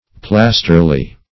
Search Result for " plasterly" : The Collaborative International Dictionary of English v.0.48: Plasterly \Plas"ter*ly\, a. Resembling plaster of Paris.